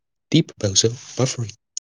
deep-bozo-buffering.wav